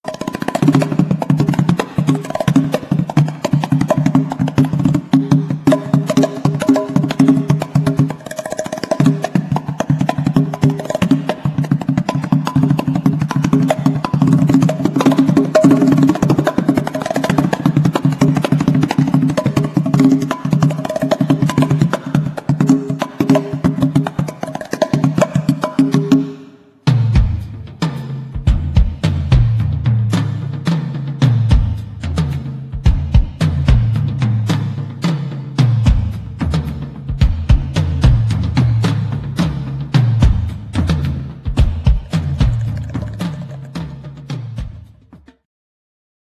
skrzypce
saksofon tenorowy, klarnet
gitara akustyczna i elektryczna
bębny, perkusja
gitara basowa i elektryczna